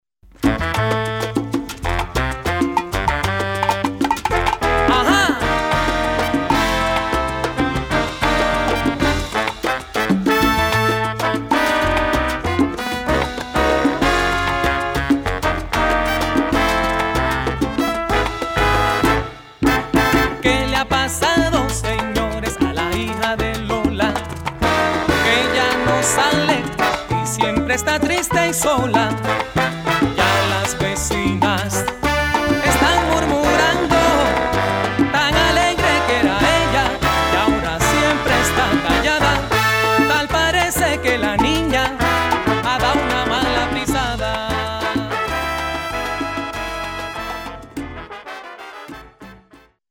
Category: salsa
Style: mambo
Solos: vocal-pregón, trombone 2
Instrumentation: trumpet 1-2, trombones 1-2, bari, rhythm
Featured Instrument: vocal